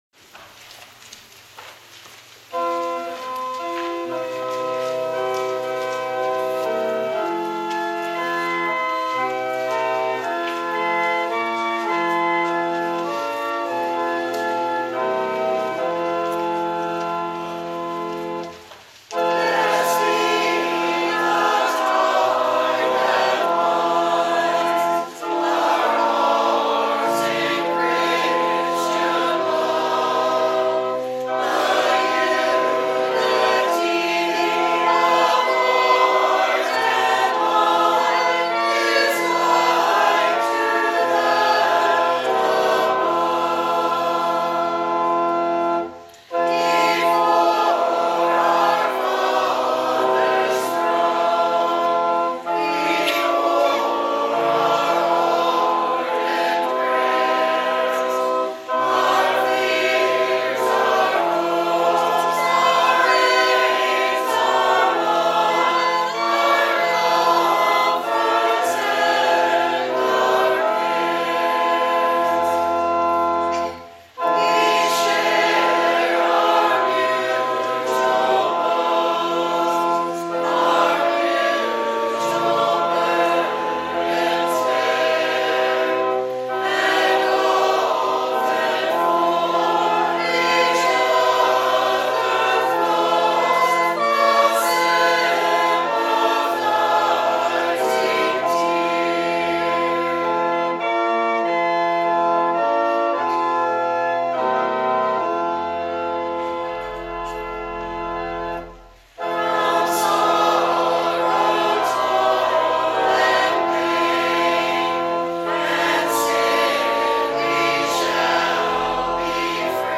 Sending Hymn "Blest Be the Tie That Binds"